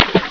snd_22231_Splash.wav